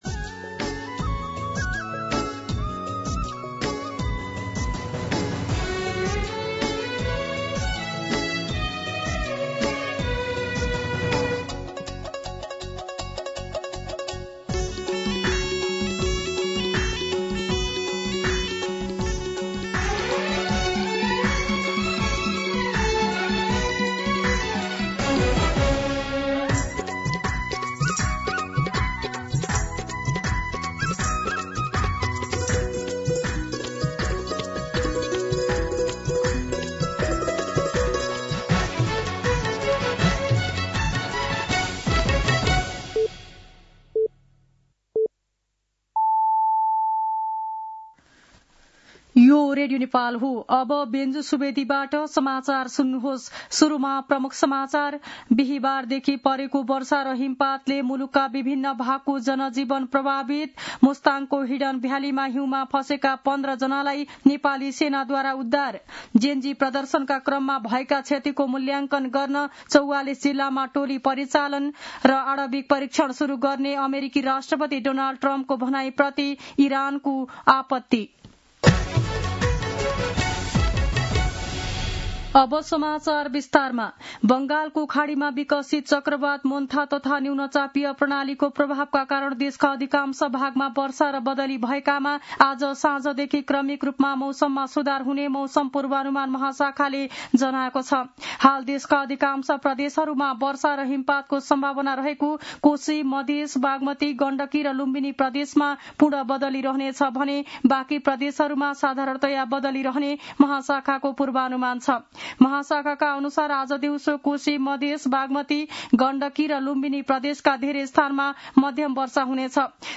दिउँसो ३ बजेको नेपाली समाचार : १४ कार्तिक , २०८२